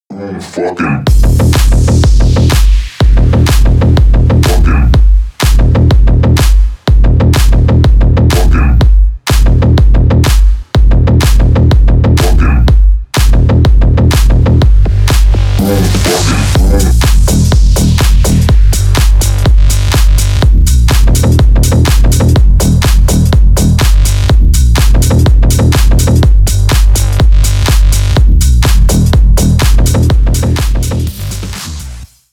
Танцевальные
клубные
громкие